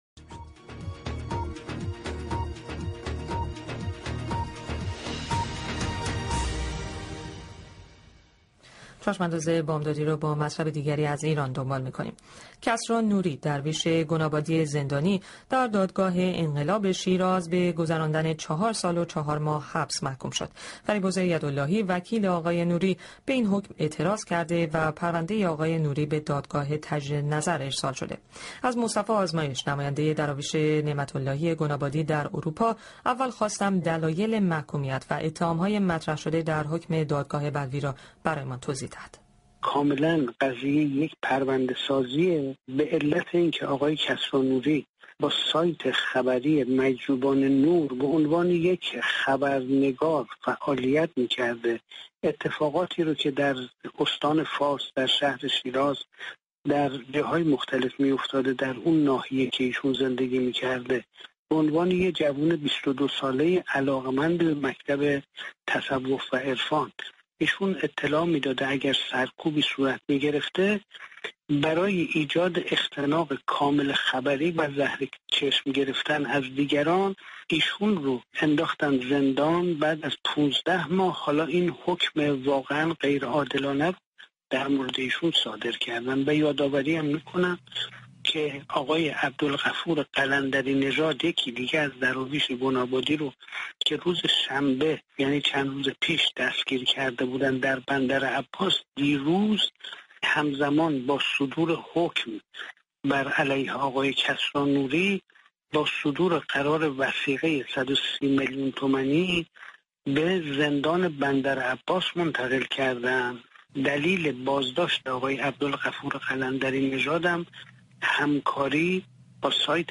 گفتگوی بی‌بی‌سی‌ فارسی